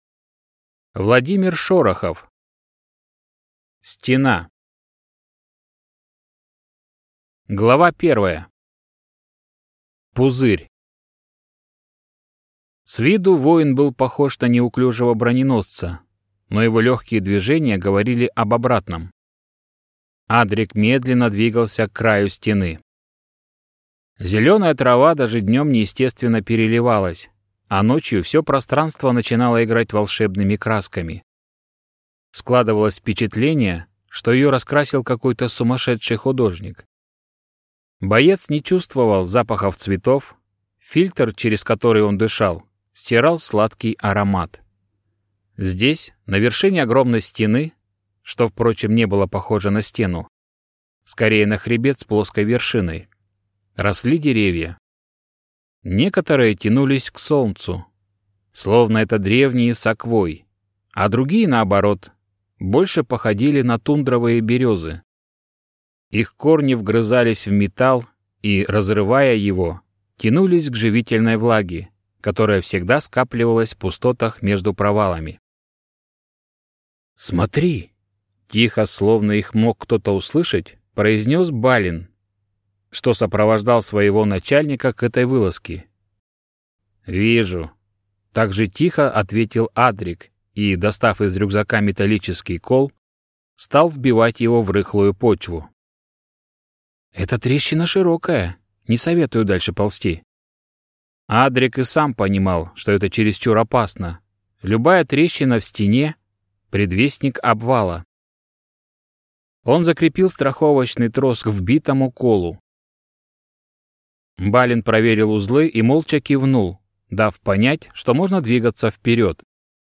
Аудиокнига Стена | Библиотека аудиокниг